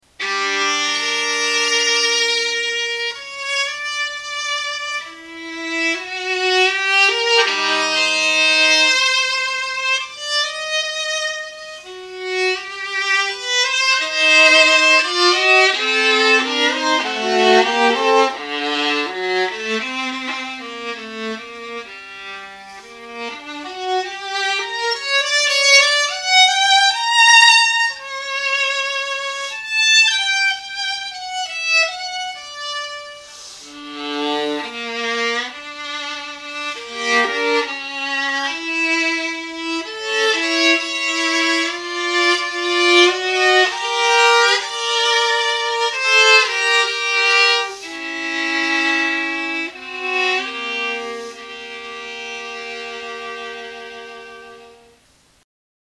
FAMILIA DE CORDA
3. A é a irmá maior do violín, de rexistro un pouco máis grave.
viola.mp3